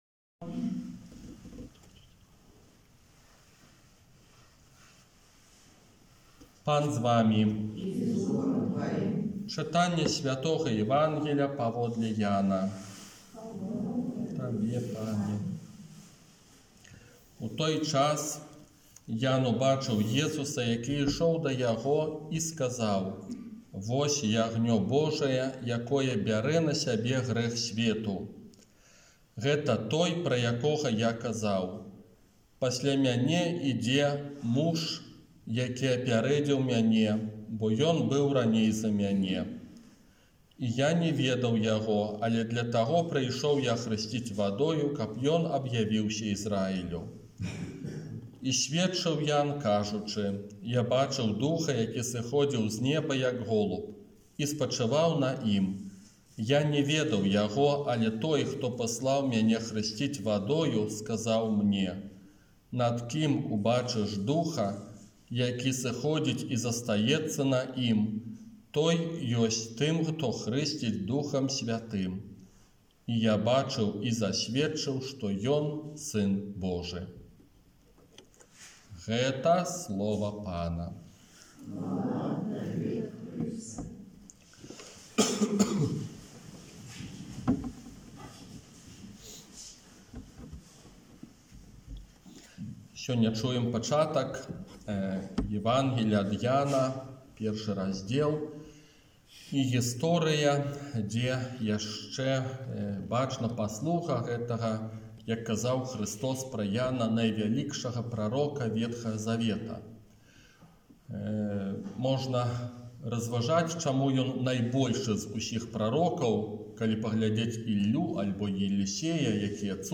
ОРША - ПАРАФІЯ СВЯТОГА ЯЗЭПА
Казанне на другую звычайную нядзелю